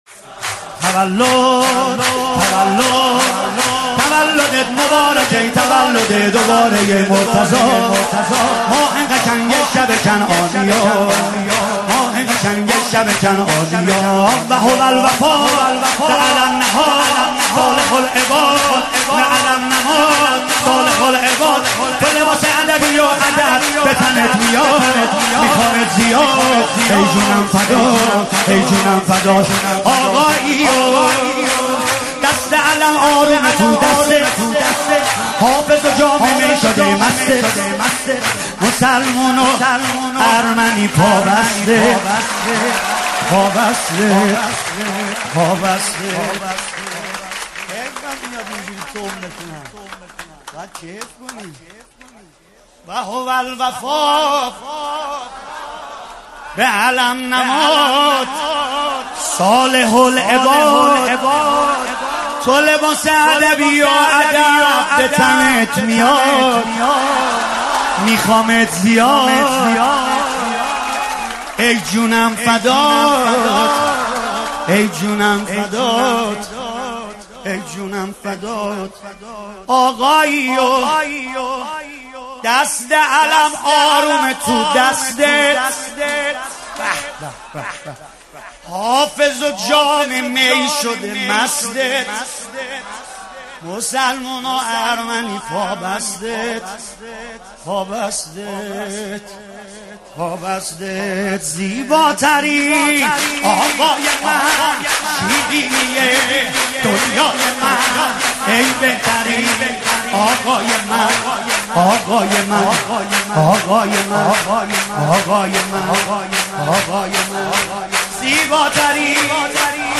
مولودی بسیار شاد و شنیدنی